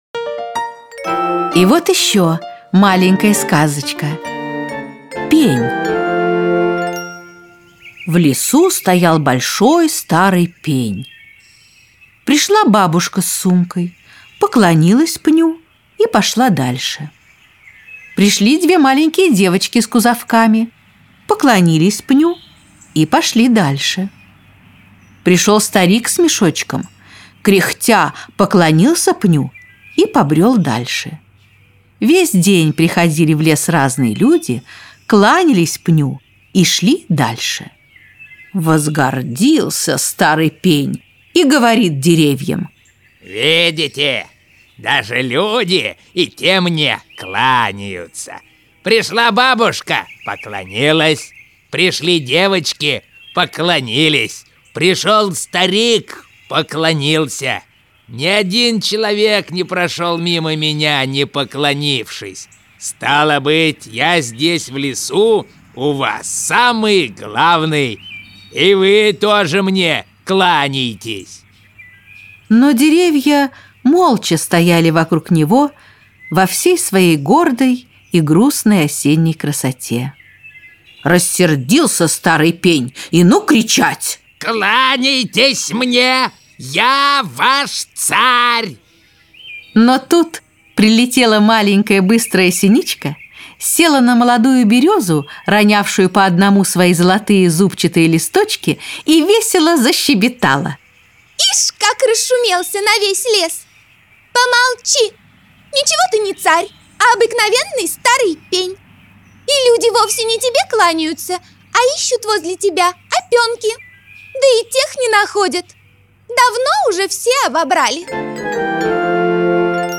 Аудиосказка «Пень»